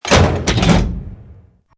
CHQ_FACT_switch_pressed.mp3